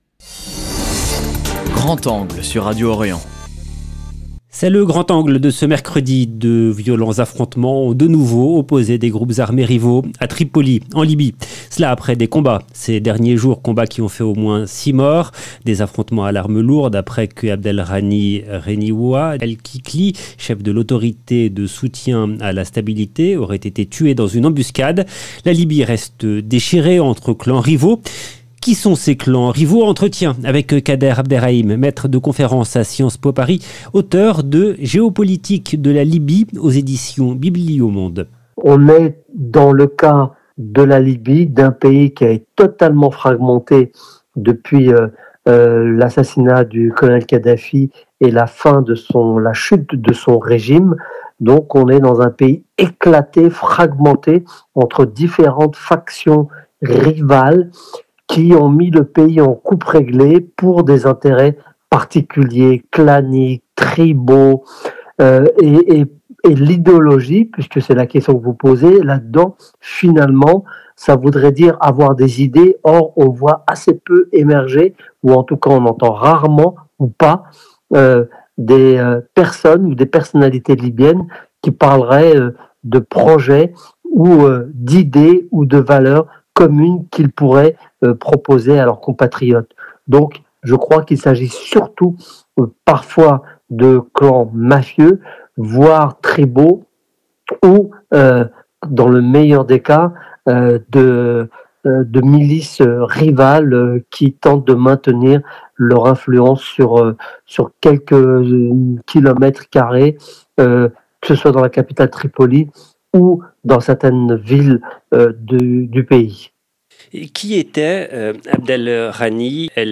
Affrontements entre groupes armés en Libye, entretien avec l'universitaire